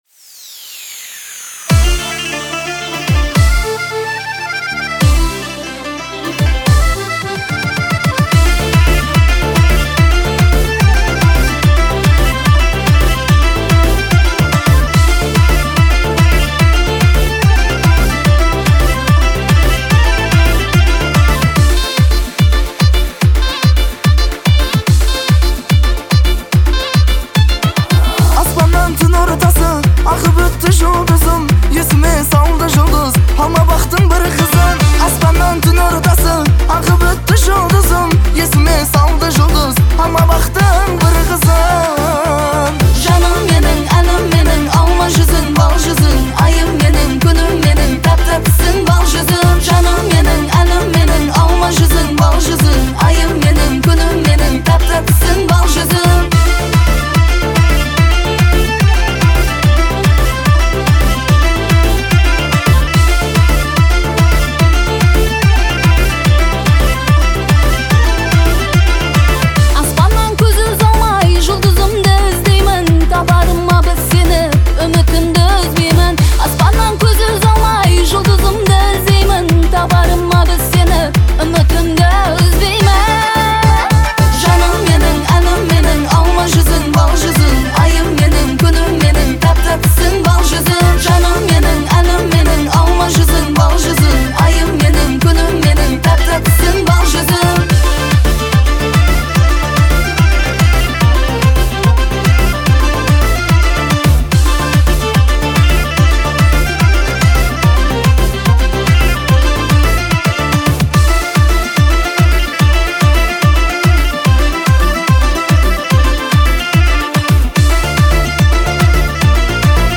это яркий пример казахского поп-рока